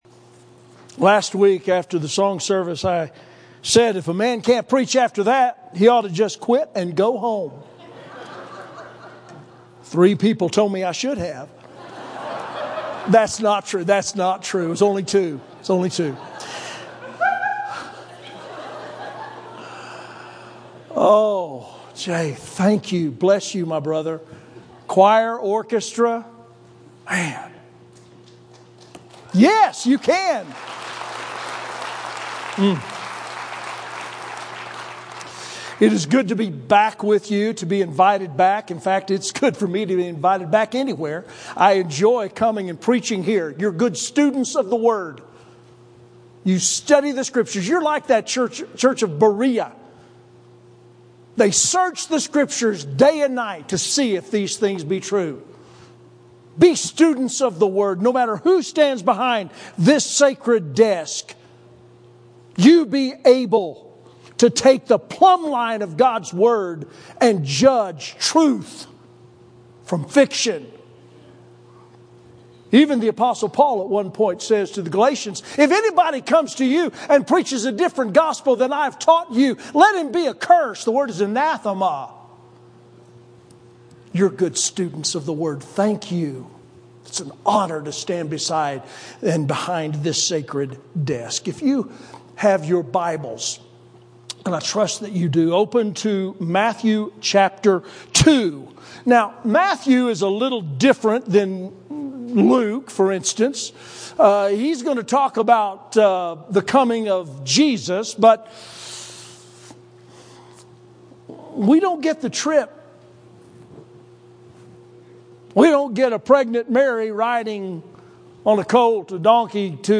Sermons - Vista Grande Baptist Church